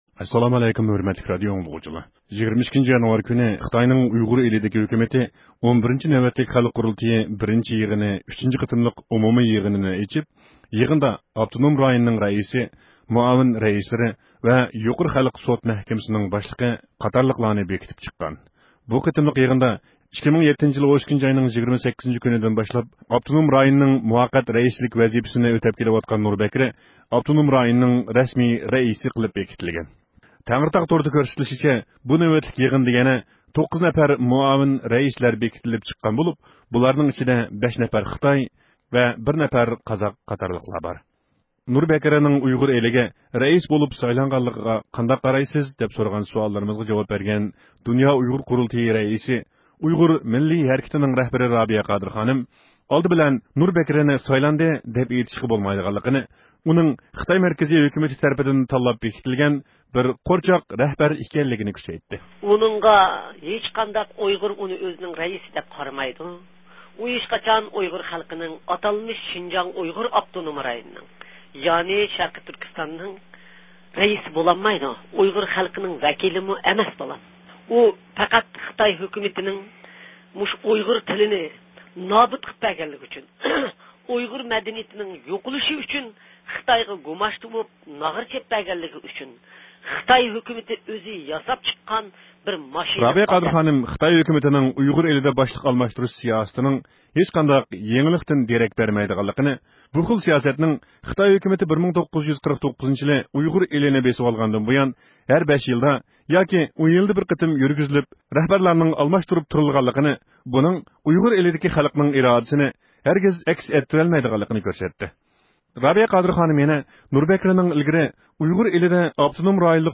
نۇر بەكرىنىڭ ئۇيغۇر ئىلىگە رەئىس بولۇپ سايلانغانلىقىغا قانداق قارايسىز دەپ سورىغان سوئاللىرىمىزغا جاۋاب بەرگەن دۇنيا ئۇيغۇر قۇرۇلتىيى رەئىسى، ئۇيغۇر مىللىي ھەرىكىتىنىڭ رەھبىرى رابىيە قادىر خانىم، ئالدى بىلەن نۇر بەكرىنى سايلاندى دەپ ئېيتىشقا بولمايدىغانلىقىنى ، ئۇنىڭ خىتاي مەركىزى ھۆكۈمىتى تەرىپىدىن تاللاپ بېكىتىلگەن بىر قورچاق رەھبەر ئىكەنلىكىنى كۆرسەتتى.